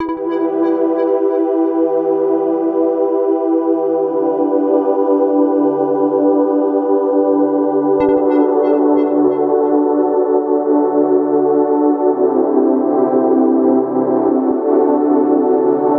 rain_city.wav